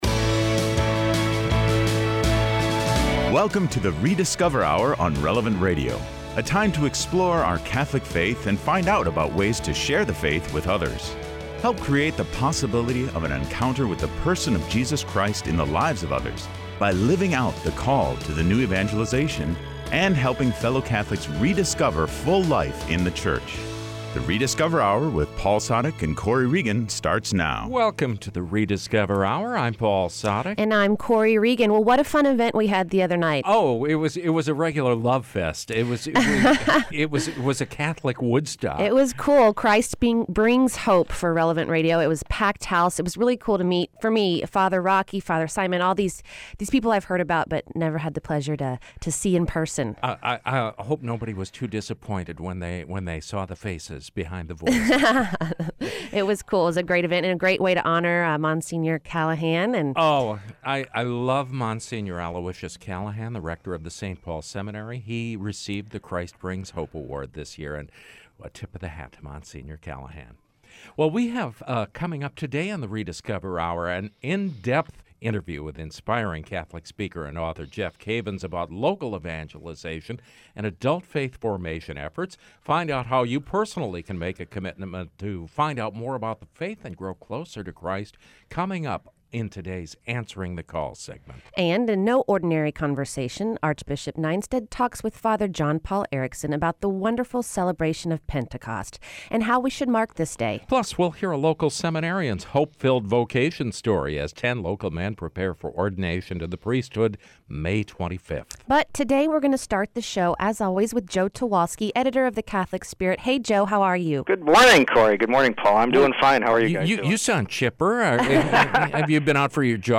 Plus, we’ll hear a local seminarian’s hope-filled vocation story, as ten local men prepare for the ordination to the priesthood on May 25.